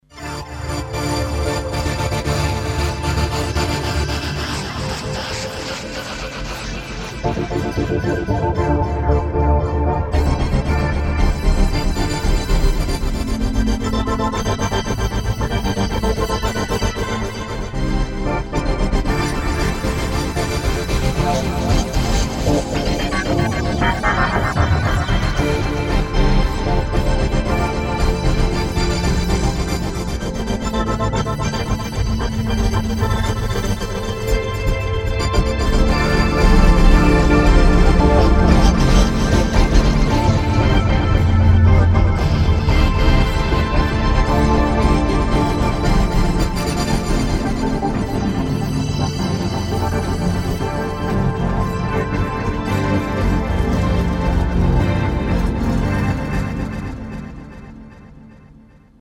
If you actually do twiddle knobs while holding the chord, things get even weirder - witness
nightskytwiddle.mp3